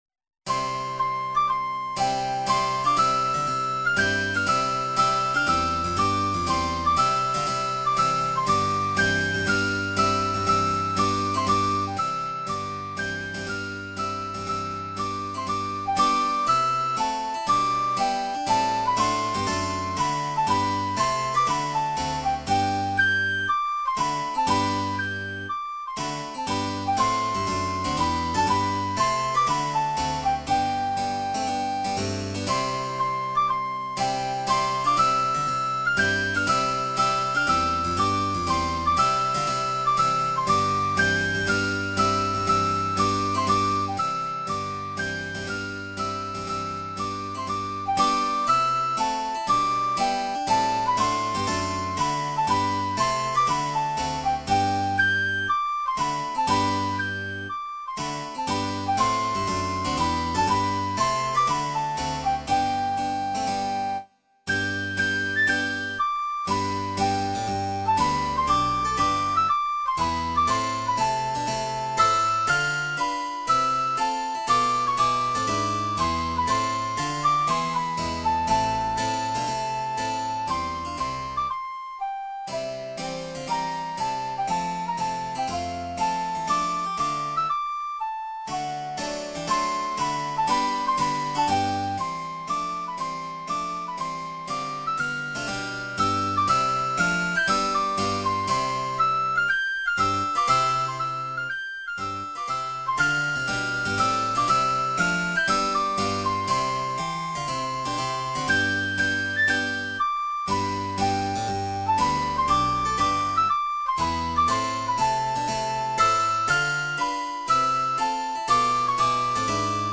Instrumentalnoten für Flöte